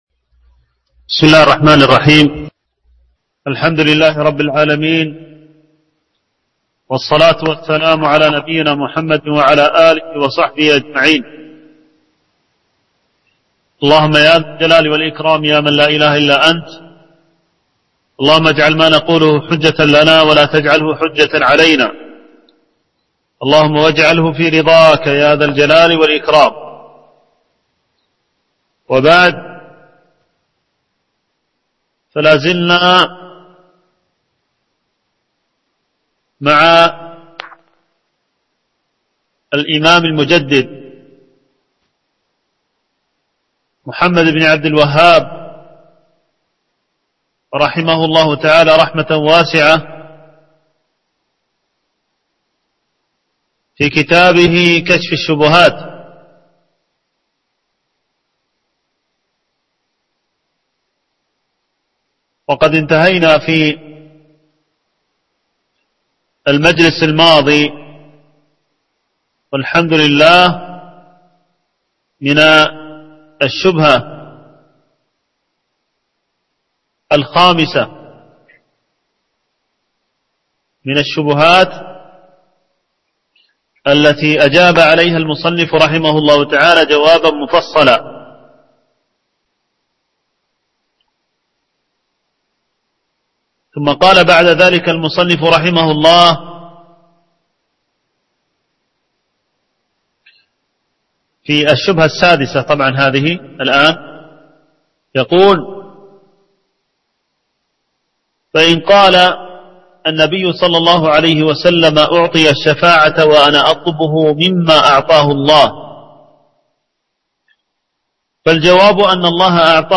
شرح كشف الشبهات - الدرس التاسع